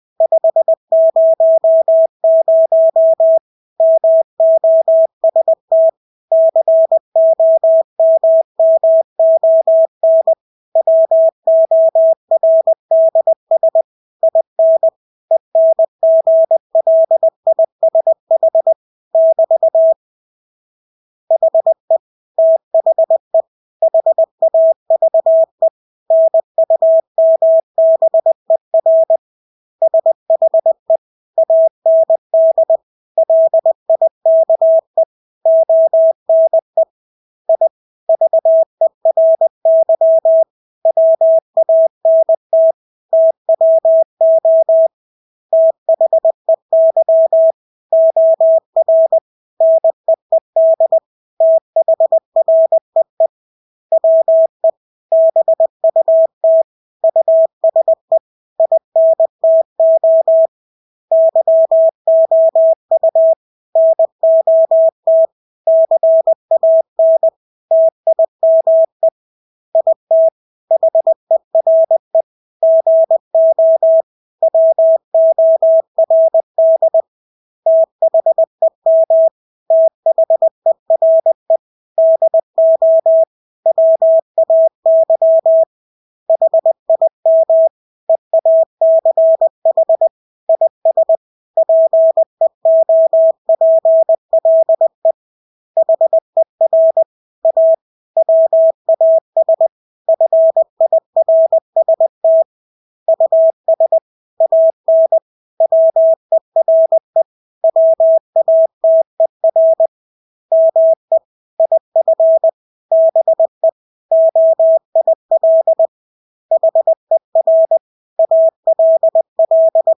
Engelske ord 20 wpm | CW med Gnister
Engelske ord 20-20 wpm.mp3